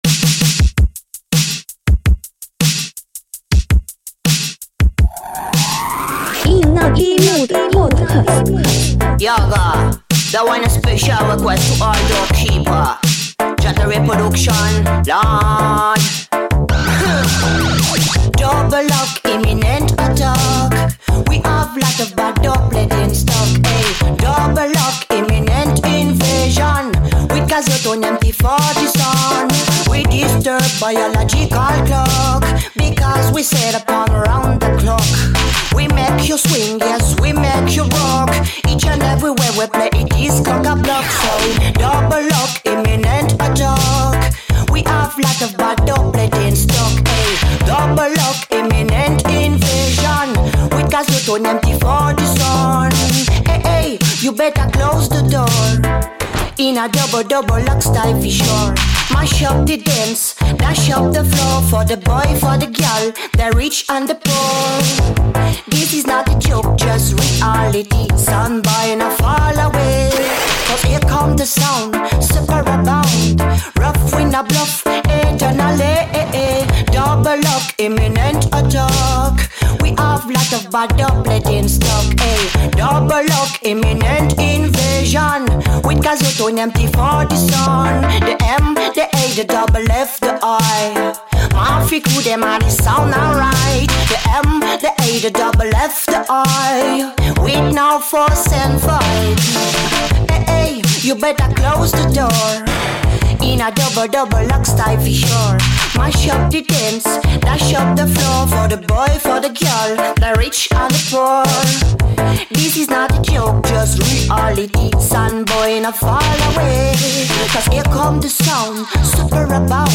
A tasty Digital Reggae podcast